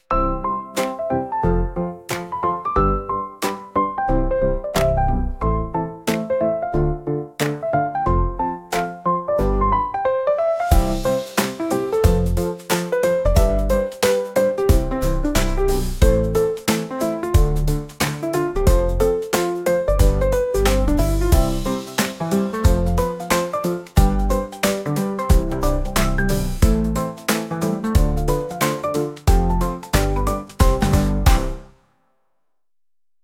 次回予告のような短いピアノ曲です 音楽素材（MP3）ファイルのダウンロード、ご利用の前に必ず下記項目をご確認ください。